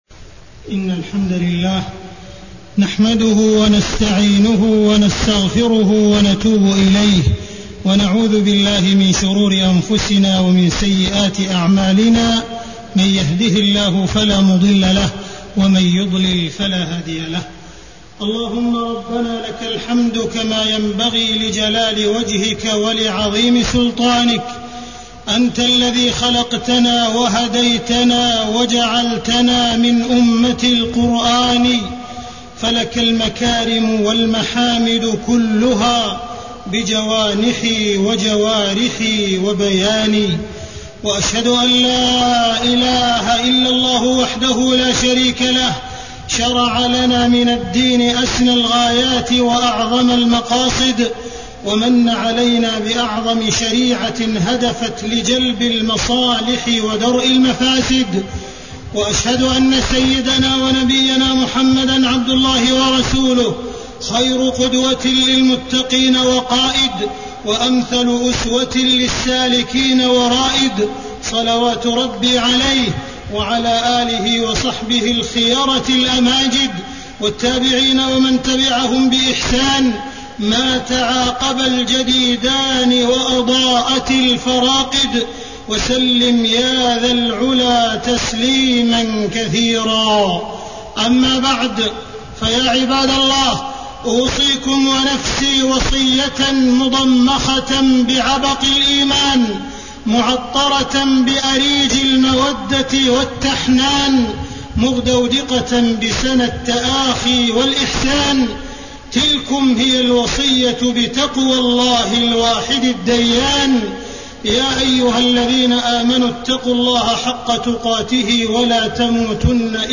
تاريخ النشر ٢٤ شوال ١٤٢٩ هـ المكان: المسجد الحرام الشيخ: معالي الشيخ أ.د. عبدالرحمن بن عبدالعزيز السديس معالي الشيخ أ.د. عبدالرحمن بن عبدالعزيز السديس مقاصد الشريعة وأهدافها